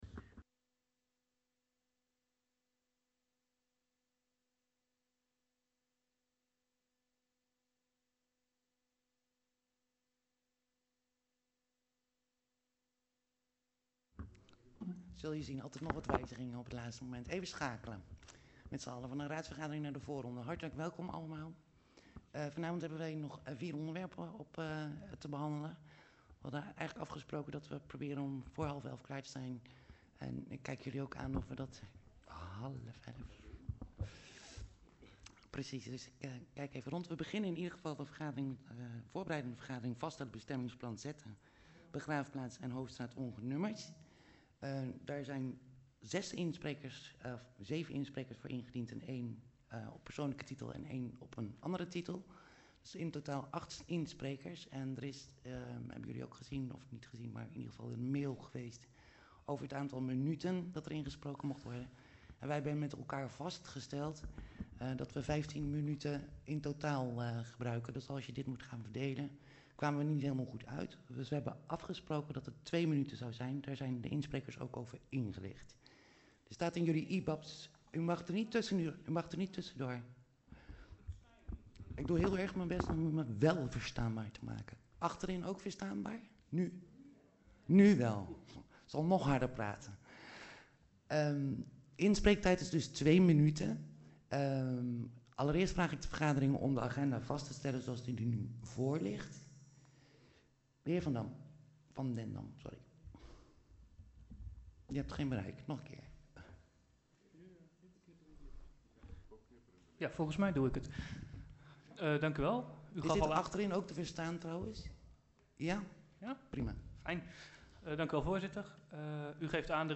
VMBO Het Westeraam Elst, tijdelijke raadzaal